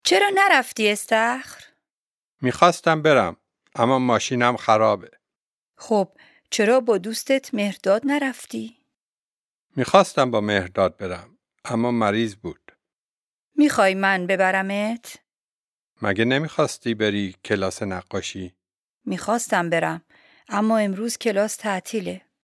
DIALOGUE 1
Dialogue1-lesson38-Farsi.mp3